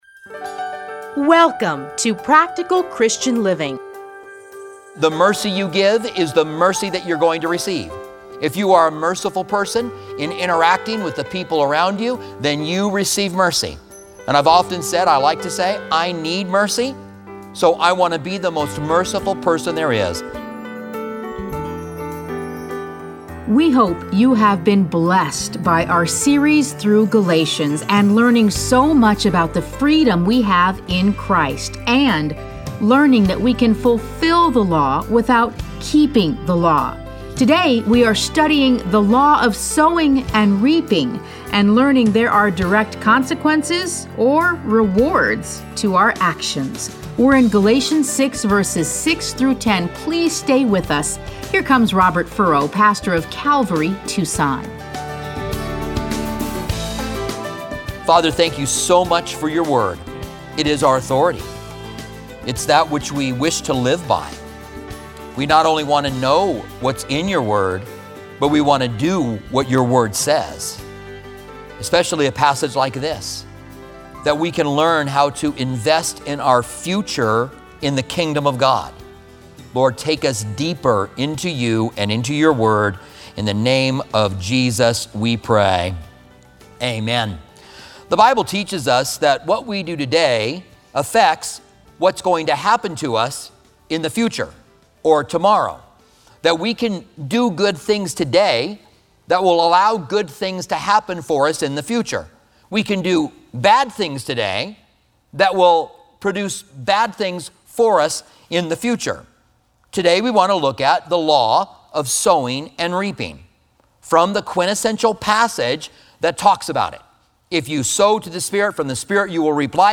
Listen to a teaching from Galatians 6:6-10.